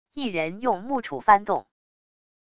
一人用木杵翻动wav下载